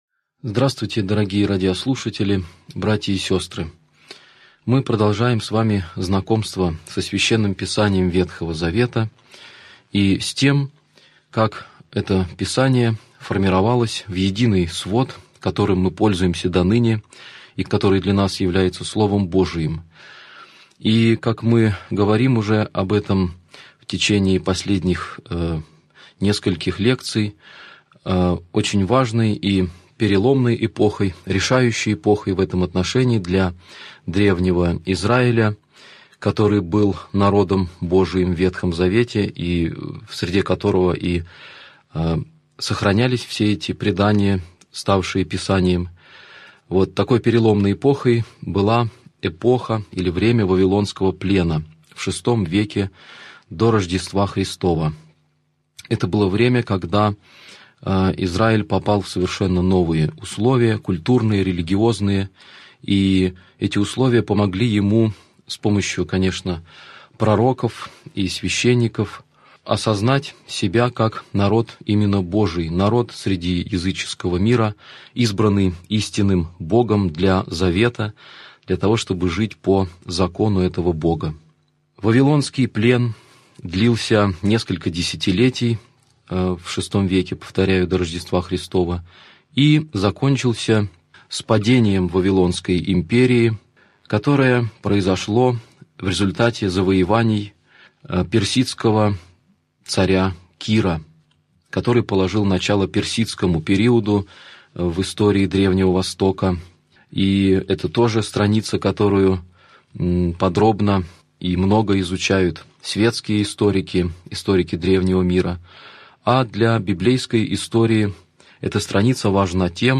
Аудиокнига Лекция 26. Возвращение из вавилонского плена | Библиотека аудиокниг